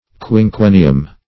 Search Result for " quinquennium" : Wordnet 3.0 NOUN (1) 1. a period of five years ; The Collaborative International Dictionary of English v.0.48: Quinquennium \Quin*quen"ni*um\, n. [L.]